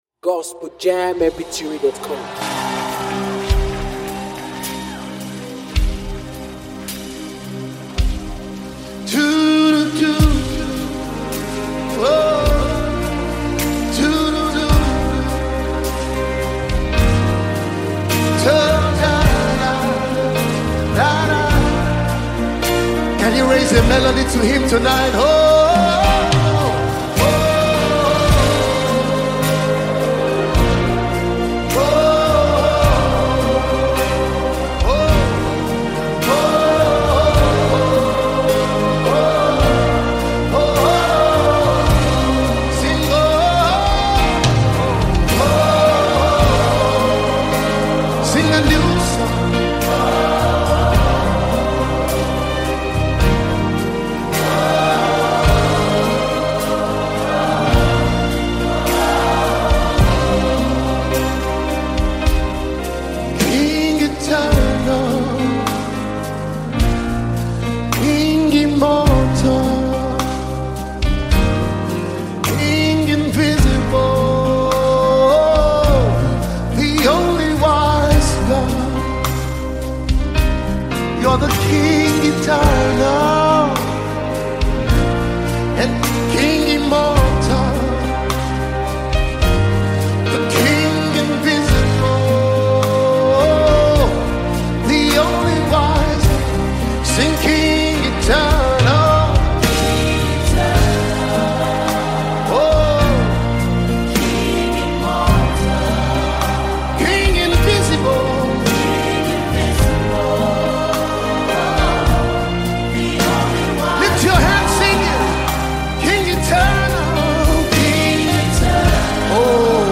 Worship
is a deeply reverent worship anthem